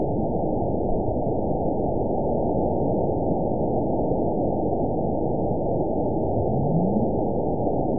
event 920557 date 03/30/24 time 00:31:45 GMT (1 year, 1 month ago) score 9.59 location TSS-AB02 detected by nrw target species NRW annotations +NRW Spectrogram: Frequency (kHz) vs. Time (s) audio not available .wav